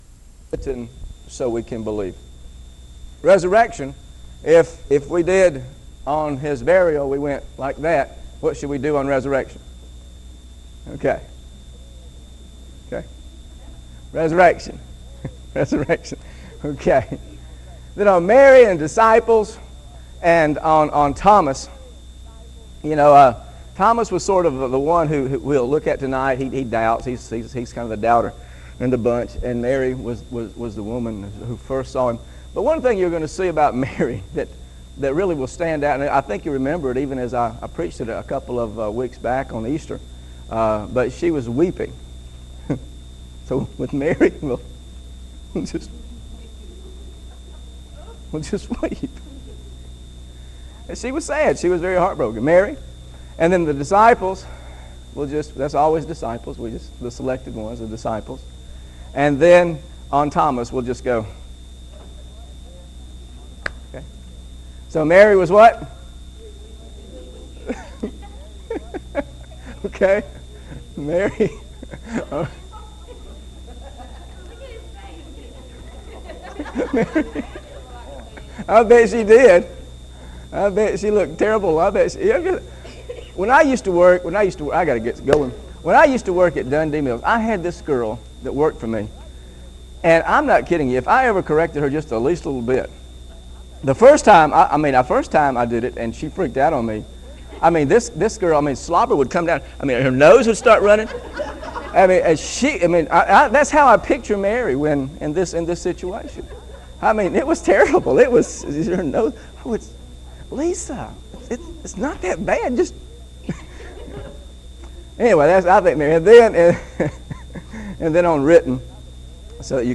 Scriptures used in this lesson: John Chapter 20 John Chapter 20 John Chapter 20 Also see